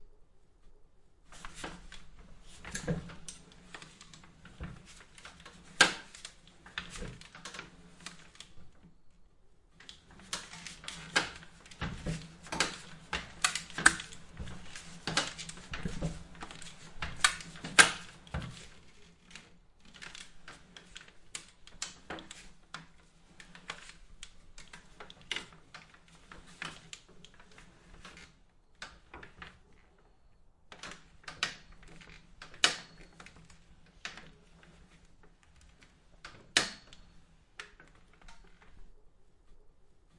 在椭圆机上行走
描述：在旧的椭圆机上锻炼。
Tag: 运动 慢跑 慢跑 散步 跑步机 椭圆 锻炼 OWI